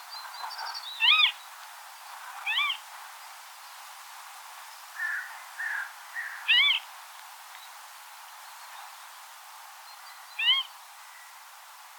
نام فارسی : سنقر تالابی
نام انگلیسی :Marsh-Harrier
نام علمی :Circus aeruginosus